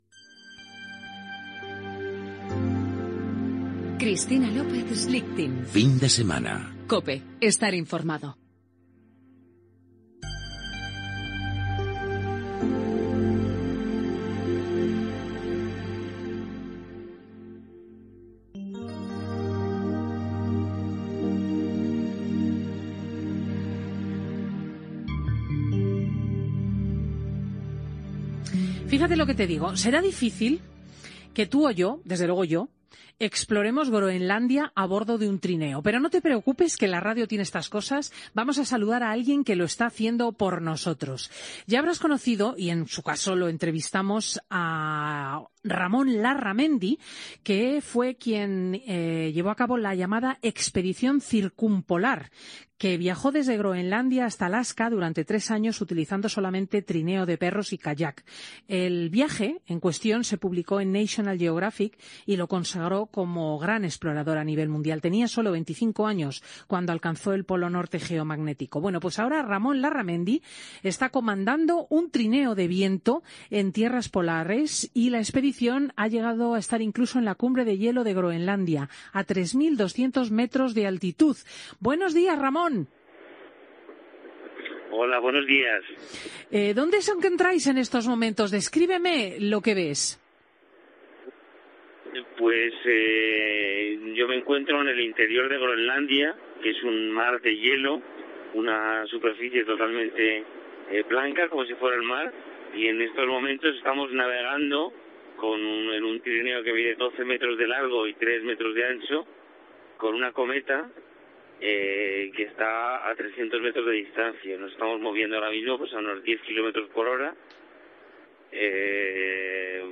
Indicatiu del programa i fragment d'una entrevista a Ramón Larramendi des d'un trineo de vent a Groenlàndia.